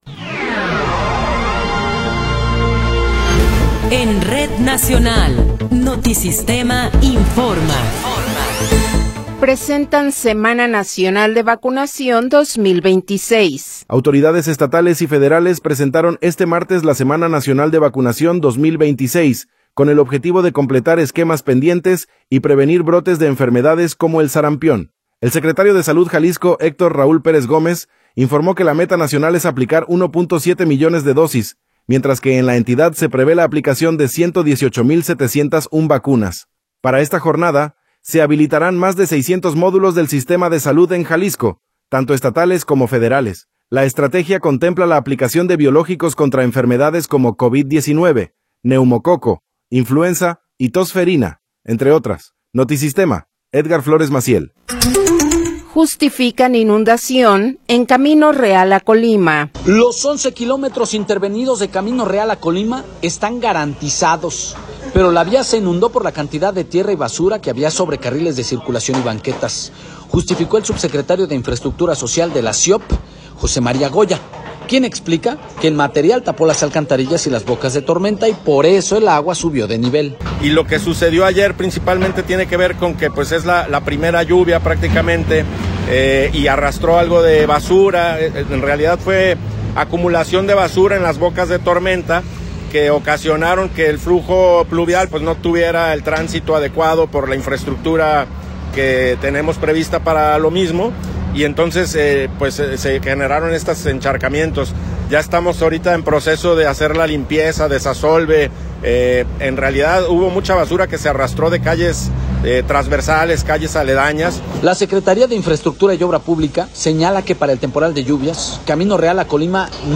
Noticiero 15 hrs. – 21 de Abril de 2026